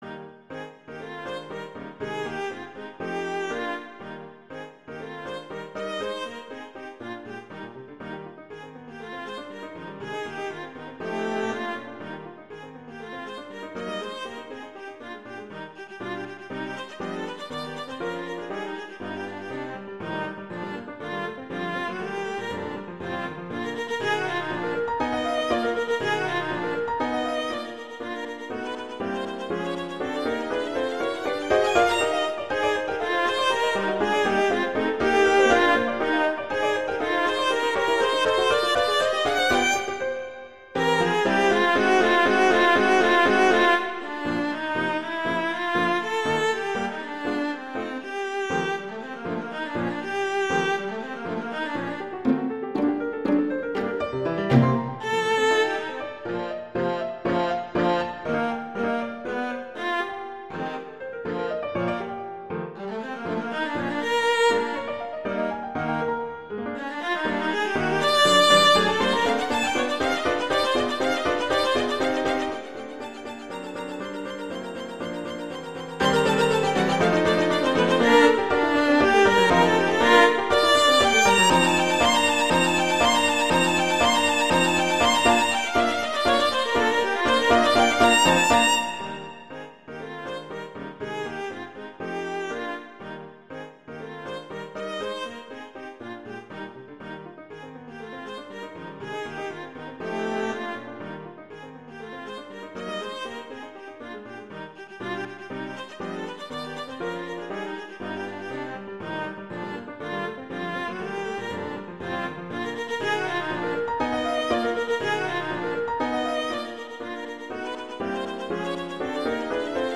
Instrumentation: viola & piano
viola and piano
classical, holiday